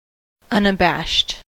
unabashed: Wikimedia Commons US English Pronunciations
En-us-unabashed.WAV